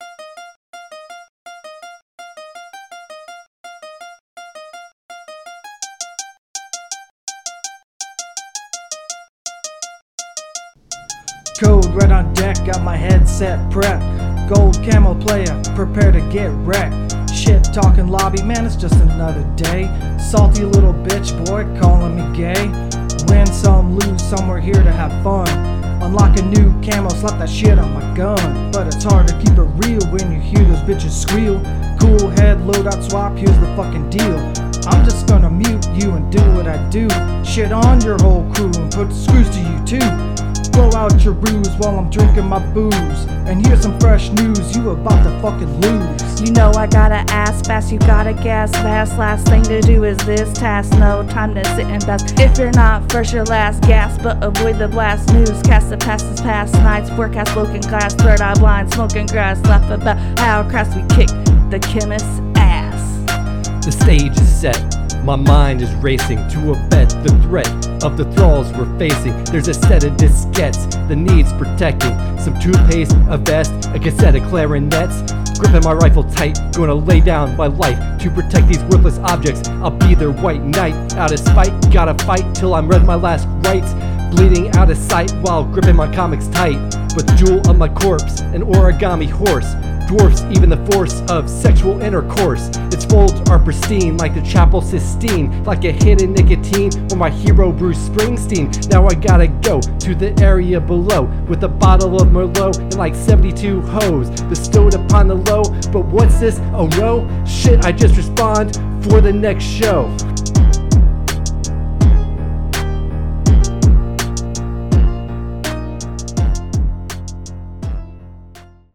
Rap from Episode 56: Call of Duty: Warzone 2.0 DMZ – Press any Button
Call-of-Duty-Warzone-2.0-DMZ-rap.mp3